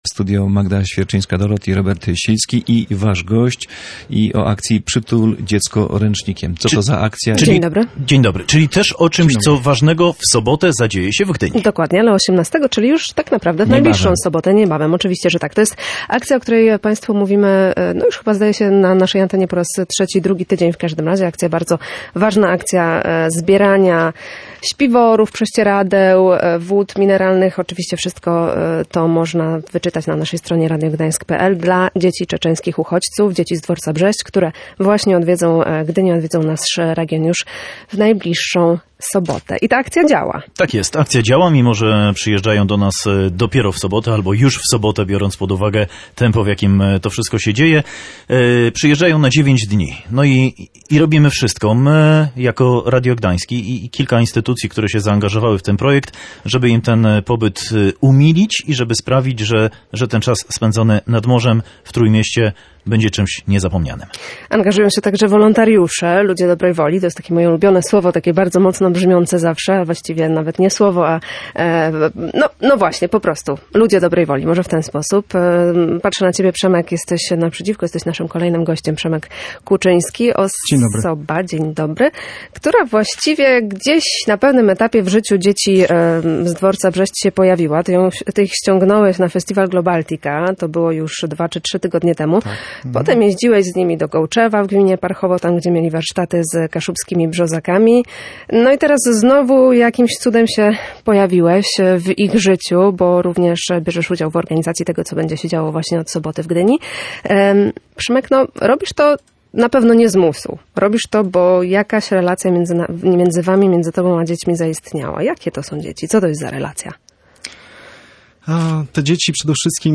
Potrafią zorganizować sobie czas, bardzo pomagają, robią dużo rzeczy, które u nas w kraju zazwyczaj robią rodzice – przyznał gość Radia Gdańsk.